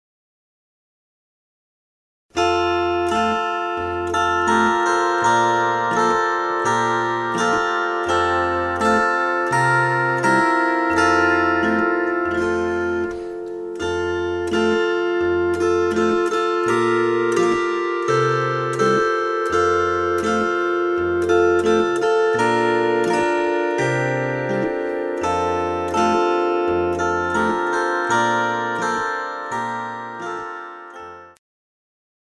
Kirchenlieder
Hörprobe   1./2. Stimme